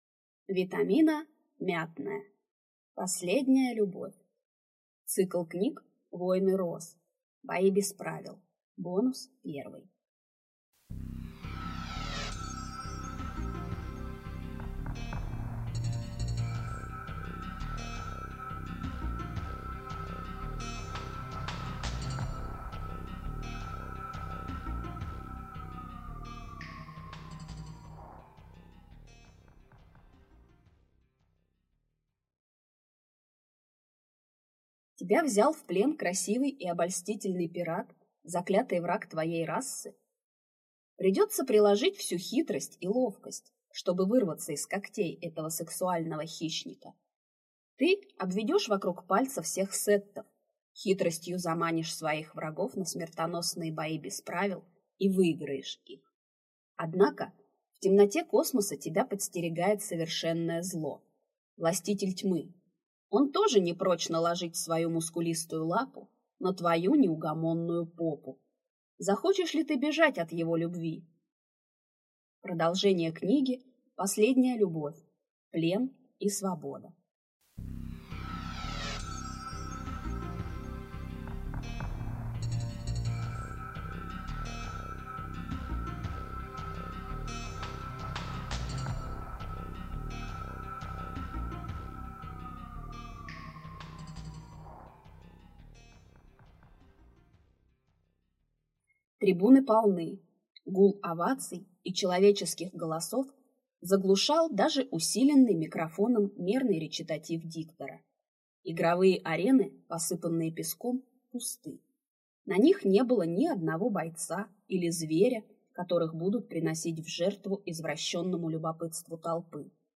Аудиокнига Бои без правил | Библиотека аудиокниг